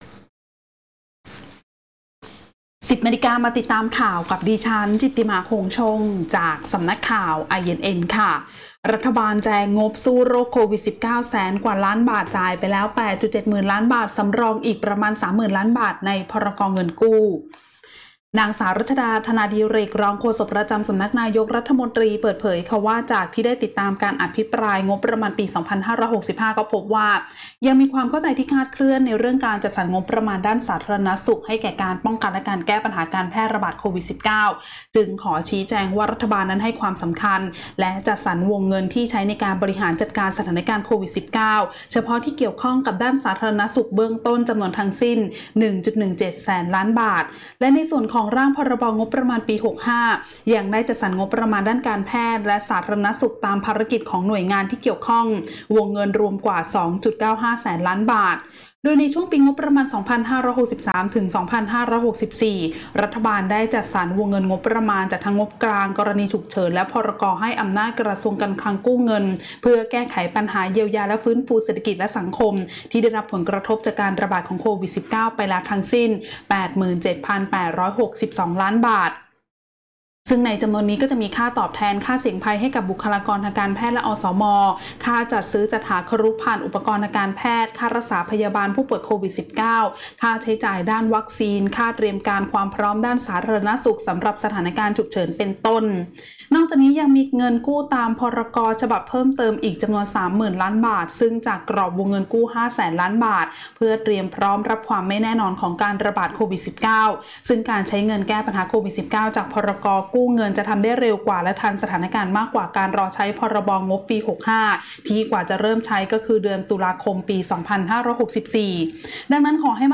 ข่าวต้นชั่วโมง 10.00 น.